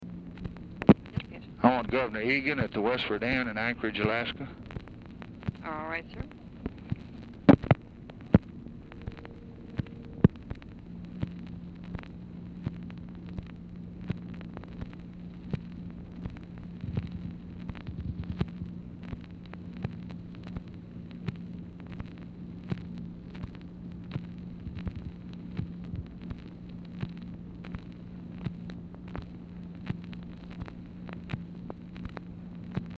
Telephone conversation # 2805, sound recording, LBJ and TELEPHONE OPERATOR, 4/1/1964, time unknown | Discover LBJ
Format Dictation belt
Oval Office or unknown location